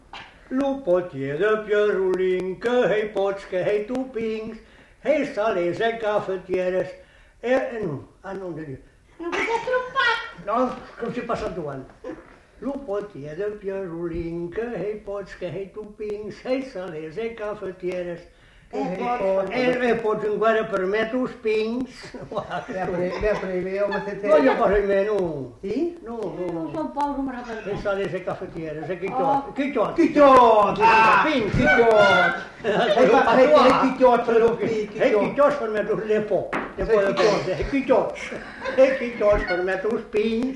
Aire culturelle : Agenais
Genre : chant
Effectif : 1
Type de voix : voix d'homme
Production du son : chanté